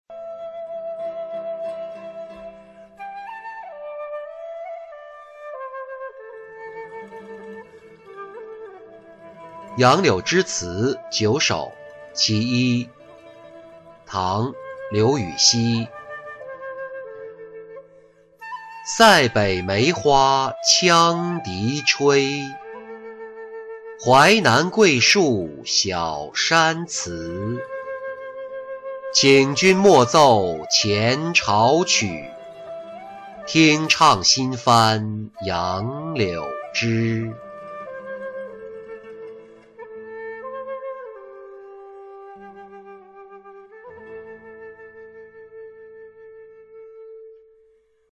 杨柳枝词九首·其一-音频朗读